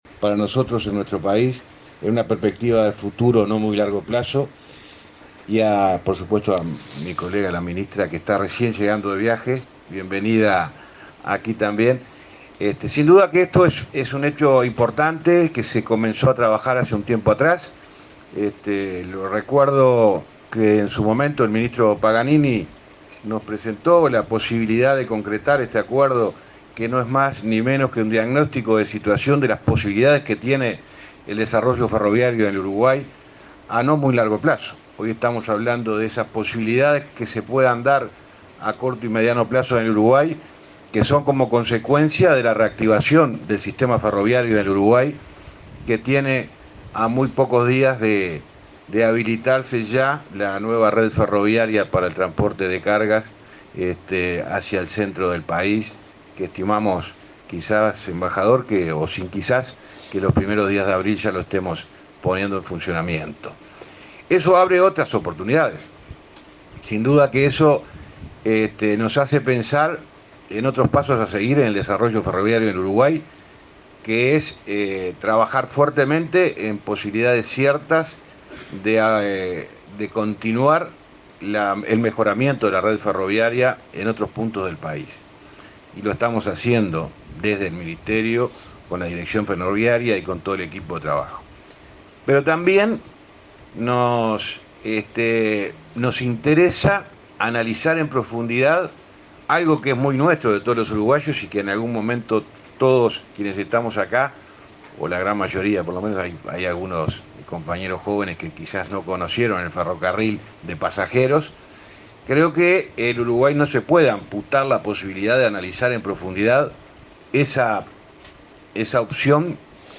Palabras del ministro de Transporte y su par de Industria